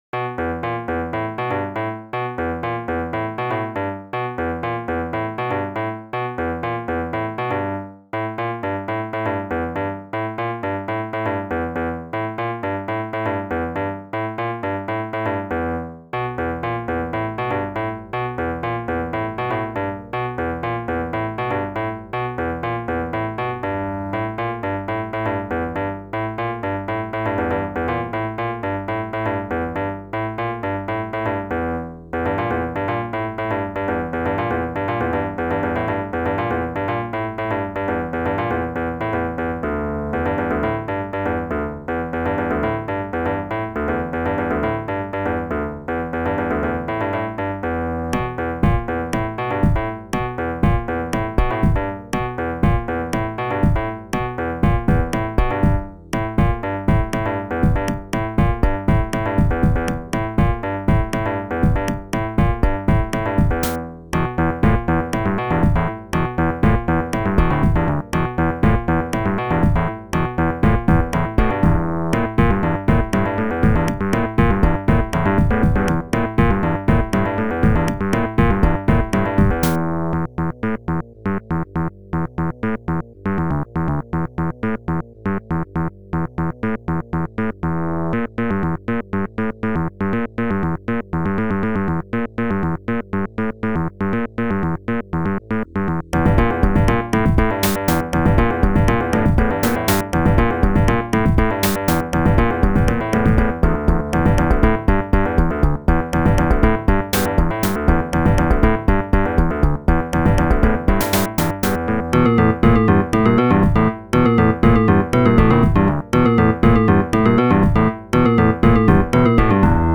Week 11 - Remaster 2 (C Blues)